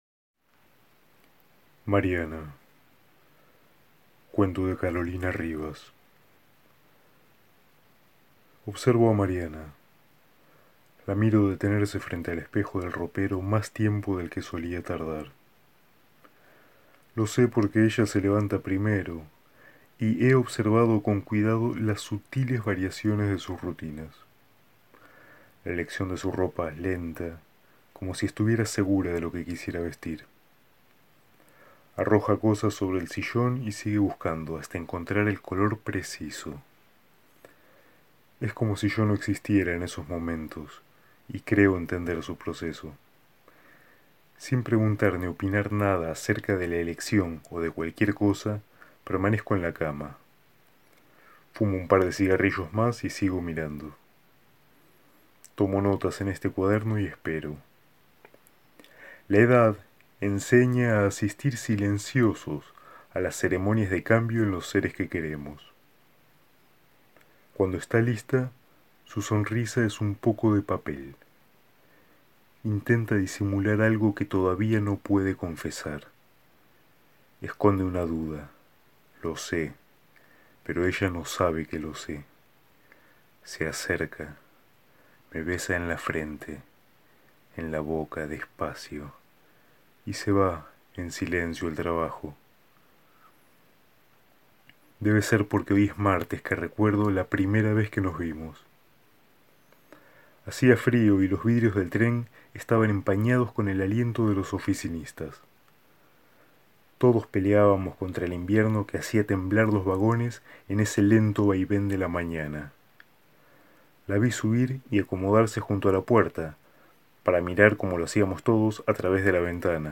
Dicho relato es un monólogo de un hombre mirando a su mujer. El envejecimiento, el devenir de los cuerpos, lo no dicho y sabido, también los supuestos arman este conmovedor texto.